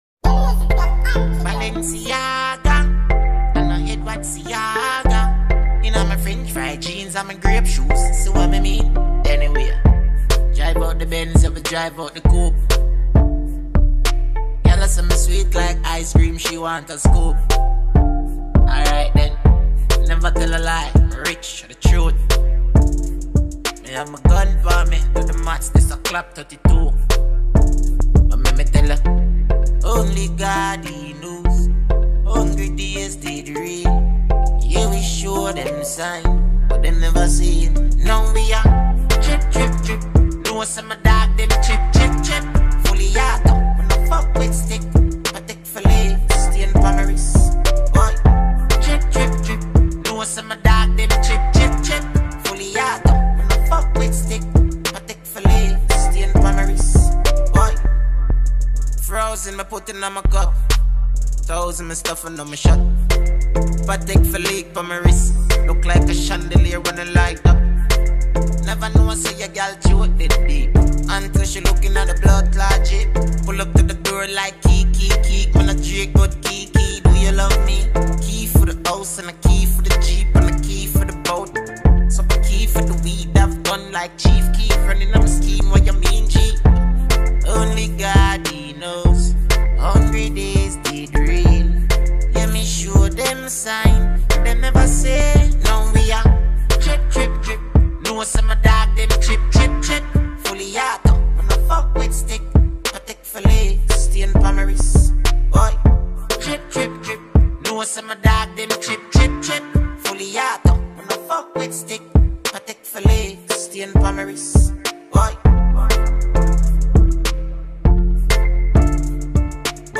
Jamaican acclaimed dancehall singer